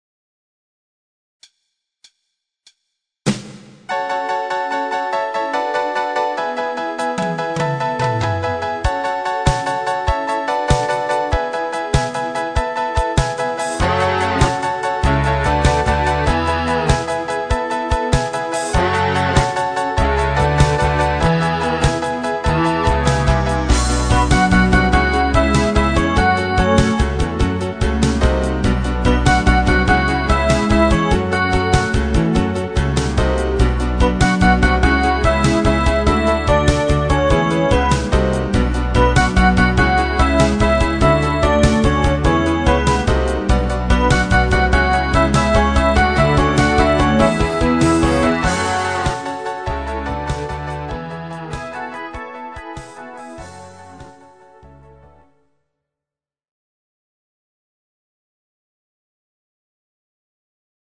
Genre(s): Internat.Pop  |  Rhythmus-Style: Rockbeat